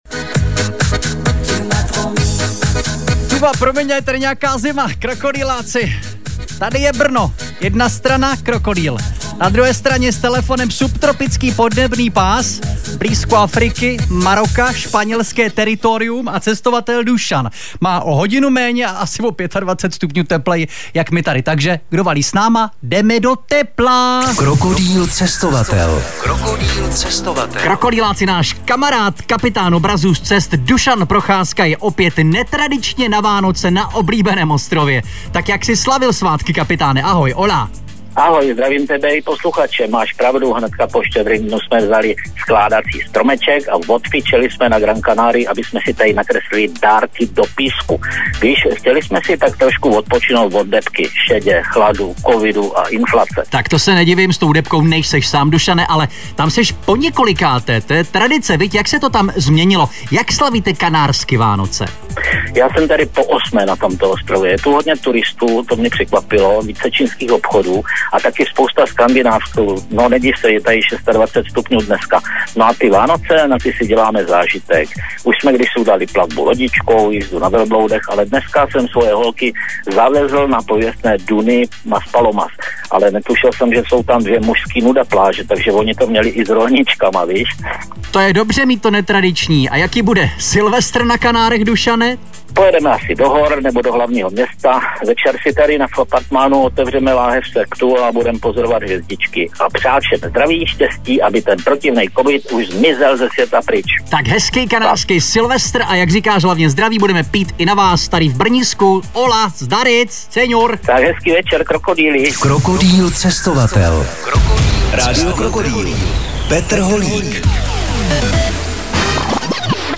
Z cesty po ostrově vznikla tato galerie i reportáž pro rádio Krokodýl